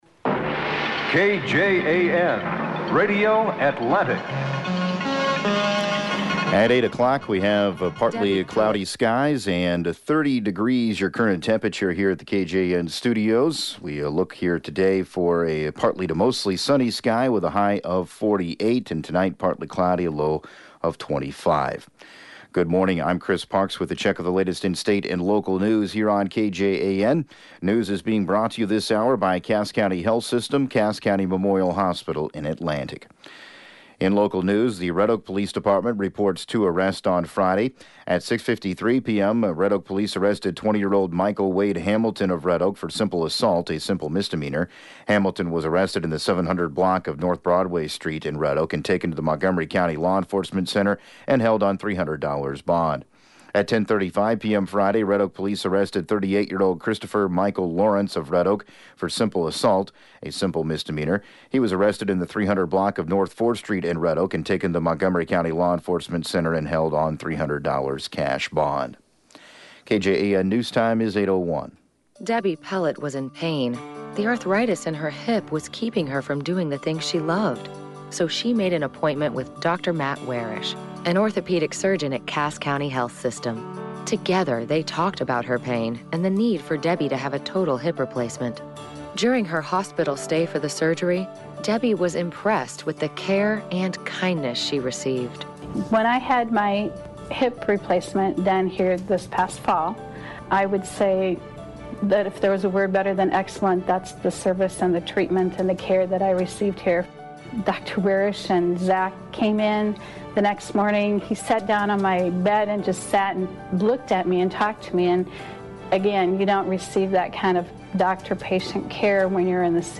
8AM Newscast 03/16/2019